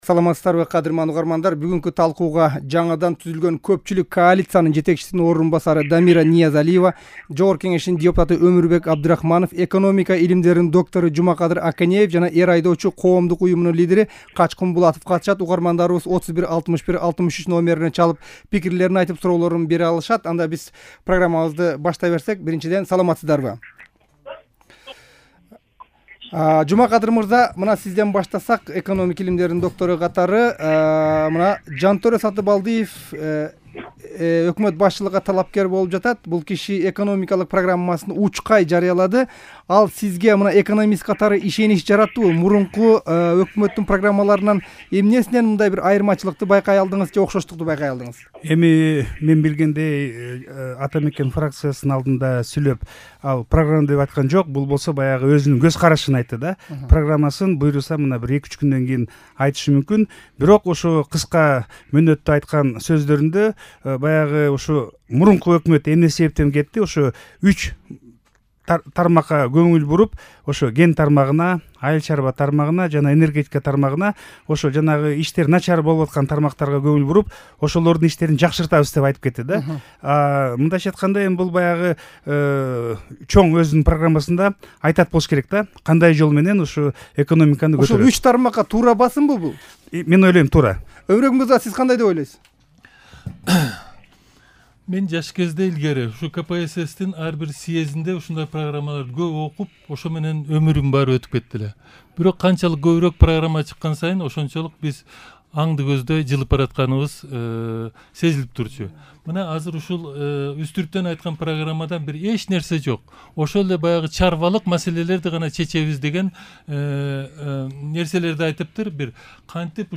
Талкууну толугу менен ушул жерден угуңуз